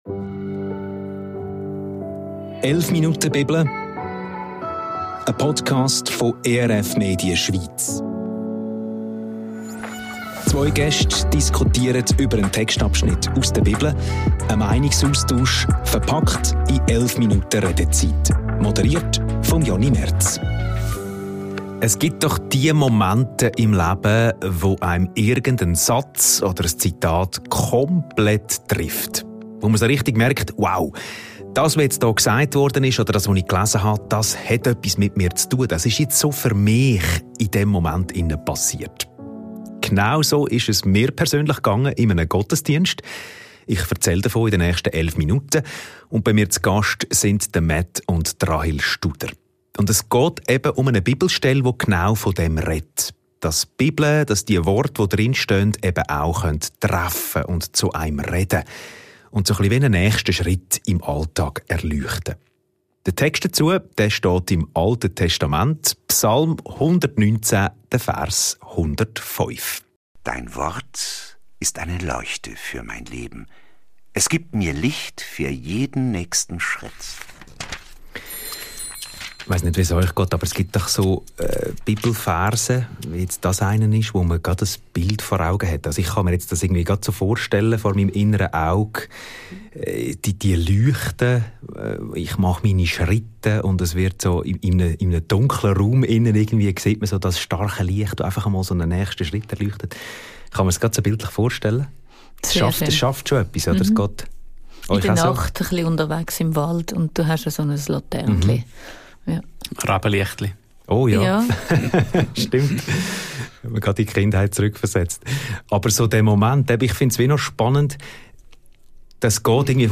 Gott will Licht in unseren Alltag bringen – Psalm 119,105 ~ 11 Minuten Bibel – ein Meinungsaustausch Podcast